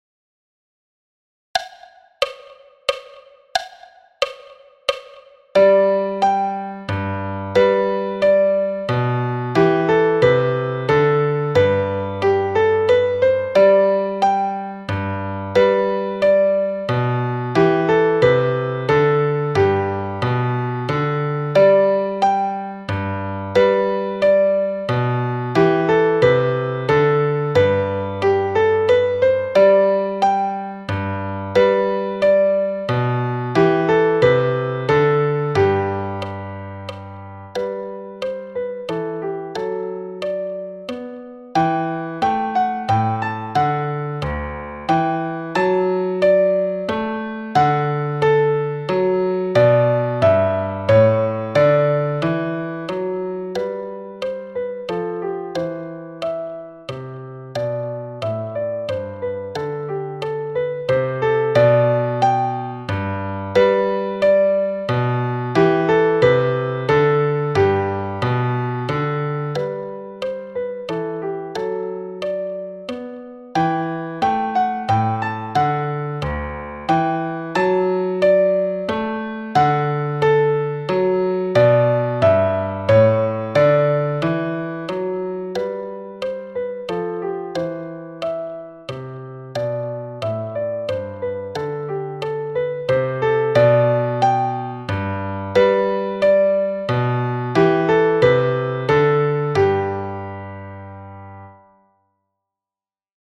Menuet de Telemann – Piano-wb à 90 bpm
Menuet-de-Telemann-Piano-wb-a-90-bpm.mp3